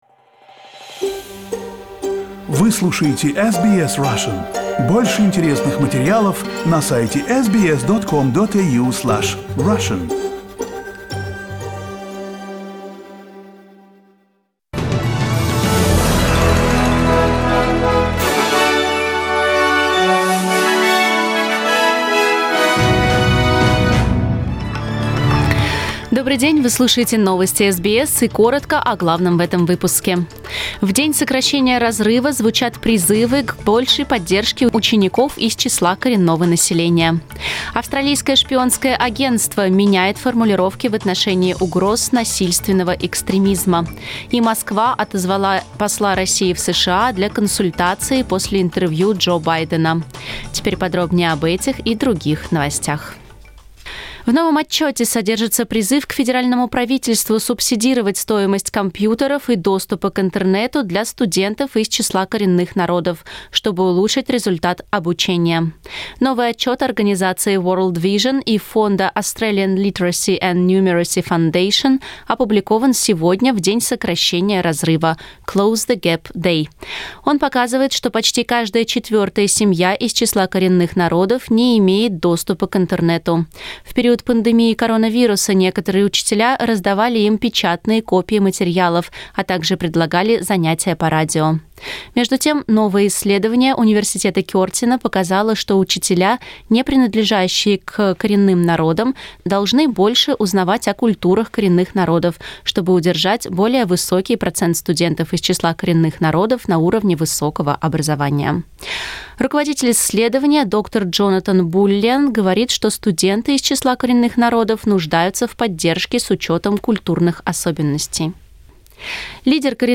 News bulletin in Russian - 18.03